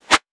metahunt/weapon_bullet_flyby_22.wav at master
weapon_bullet_flyby_22.wav